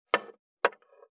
483,切る,包丁,厨房,台所,野菜切る,咀嚼音,ナイフ,調理音,まな板の上,料理,
効果音厨房/台所/レストラン/kitchen食器食材